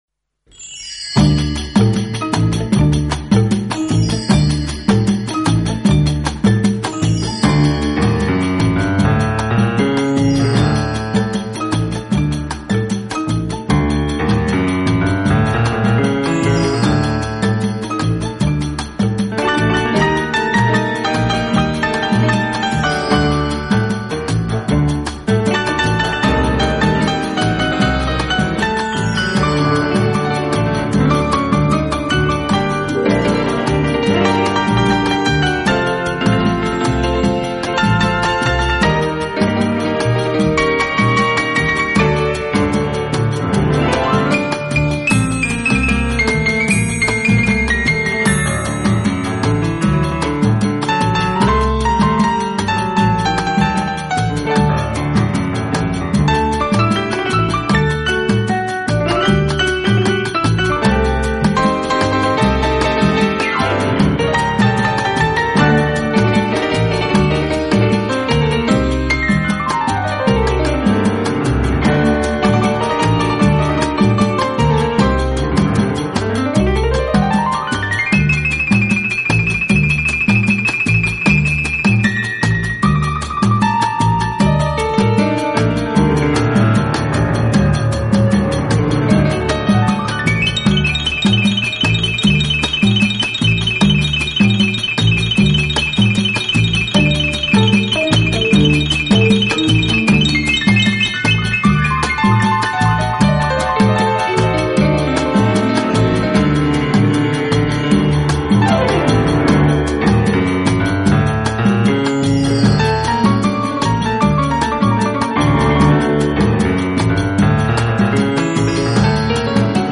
【双钢琴】